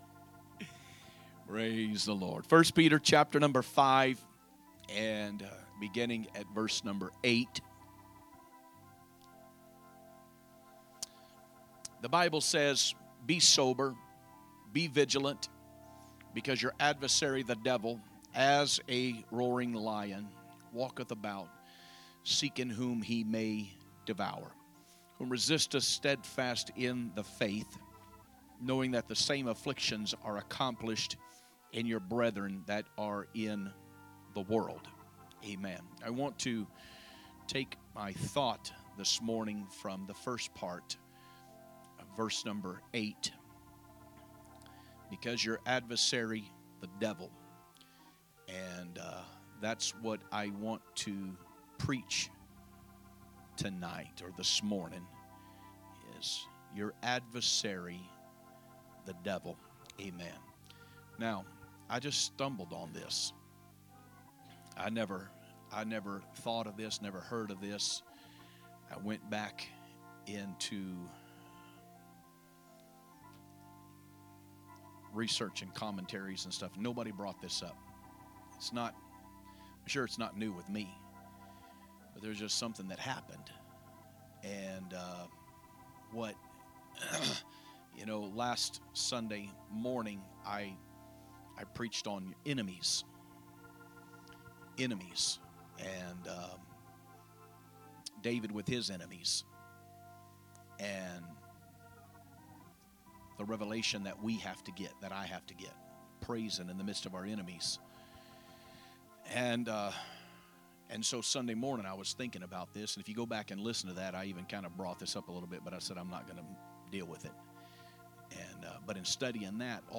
Sunday Morning Message